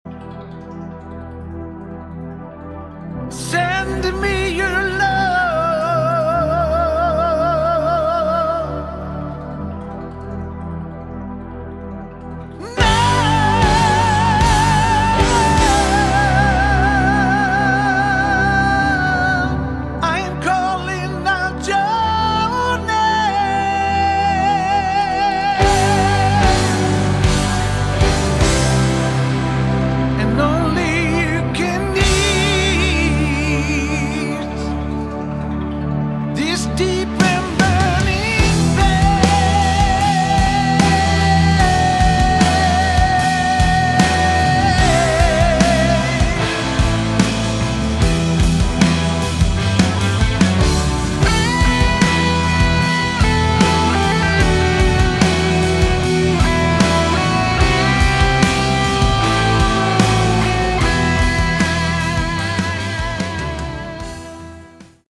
Category: AOR / Melodic Rock
vocals
guitars
drums